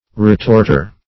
Retorter \Re*tort"er\, n. One who retorts.